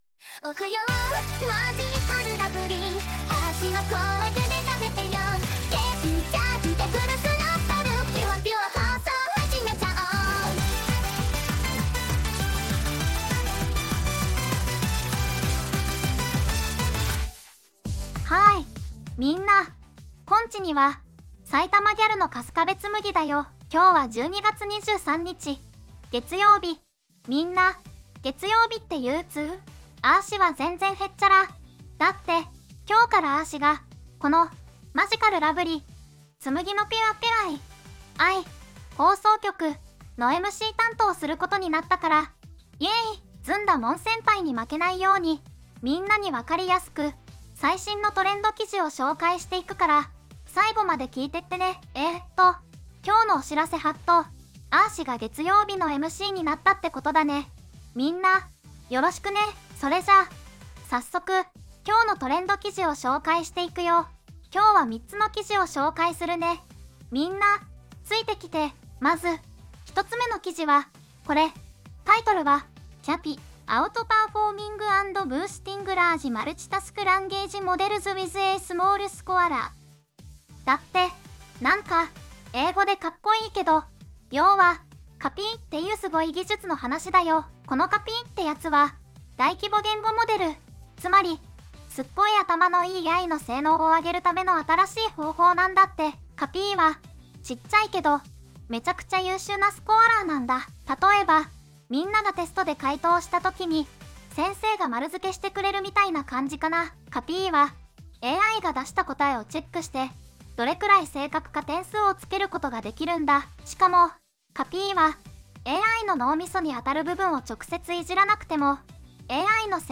VOICEVOX:春日部つむぎ